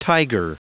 Prononciation du mot tiger en anglais (fichier audio)
Prononciation du mot : tiger